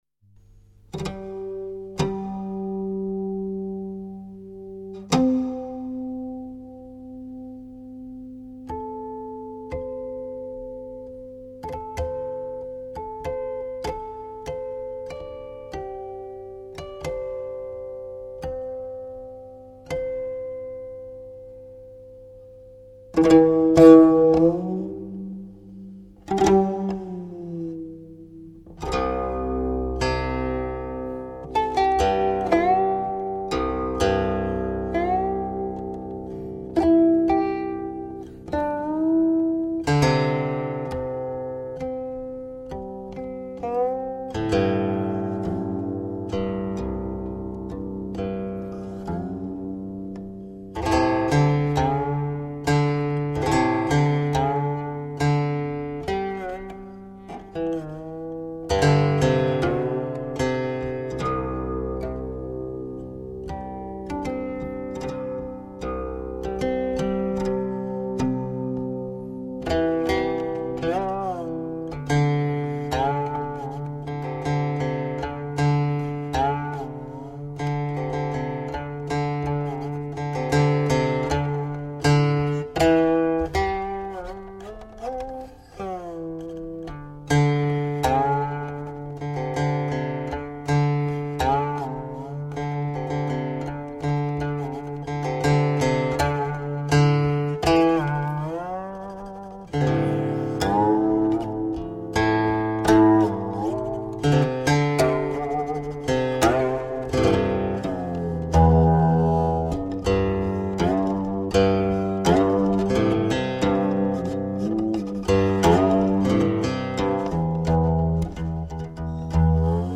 中乐试音碟皇
极品民乐曲目